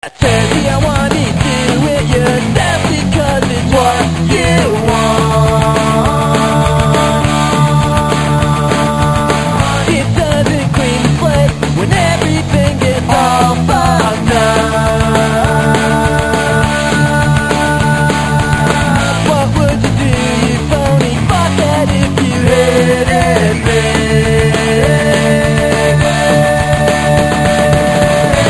lead guitar and vocals